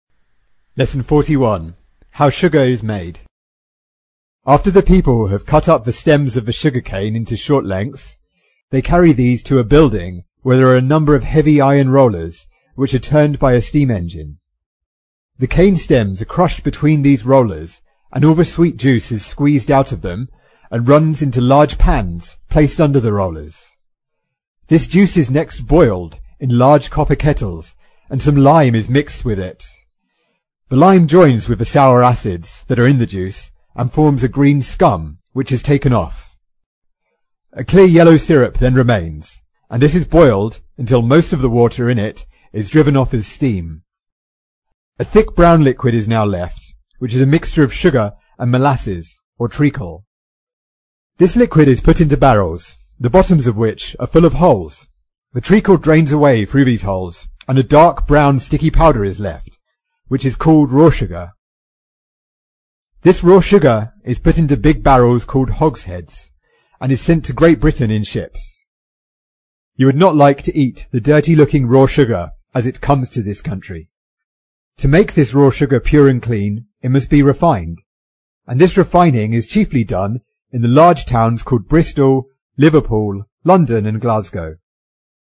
在线英语听力室英国学生科学读本 第41期:糖是怎么生产的(1)的听力文件下载,《英国学生科学读本》讲述大自然中的动物、植物等广博的科学知识，犹如一部万物简史。在线英语听力室提供配套英文朗读与双语字幕，帮助读者全面提升英语阅读水平。